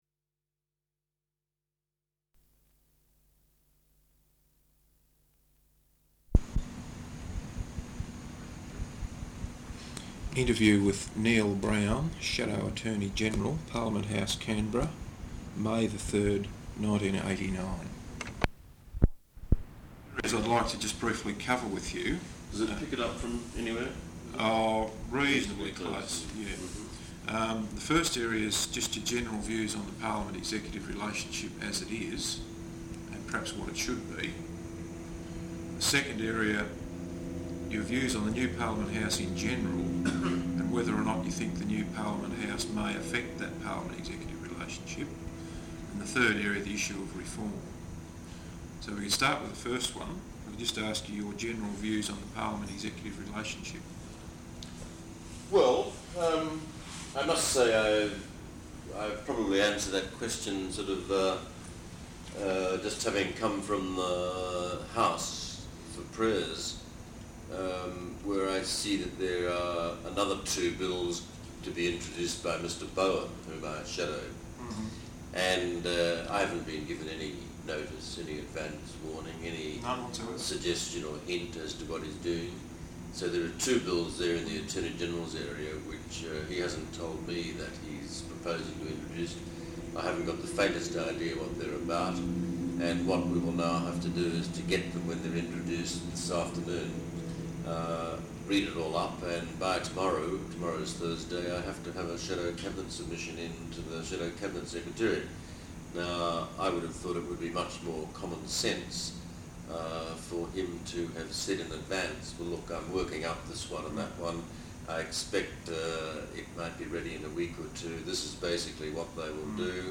Interview with Neil Brown, Shadow Attorney General, Parliament House, Canberra May 3rd 1989.